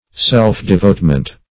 Self-devotement \Self`-de*vote"ment\, n.
self-devotement.mp3